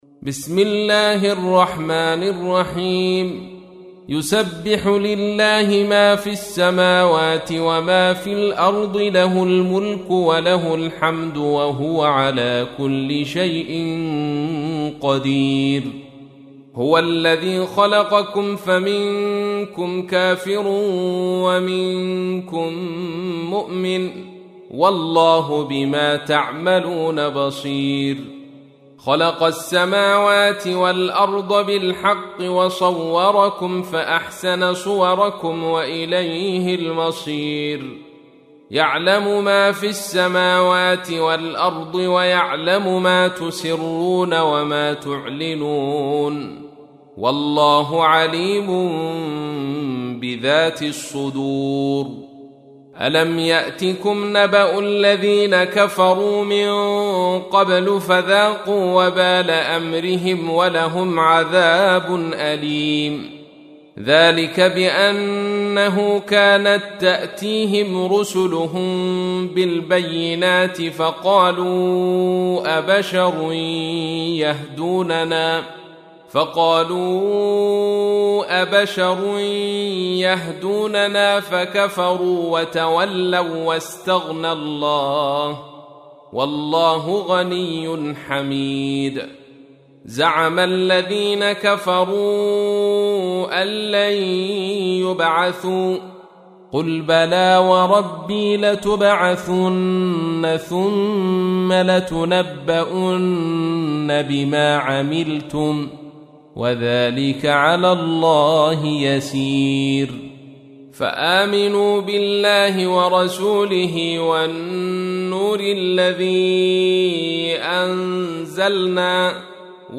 تحميل : 64. سورة التغابن / القارئ عبد الرشيد صوفي / القرآن الكريم / موقع يا حسين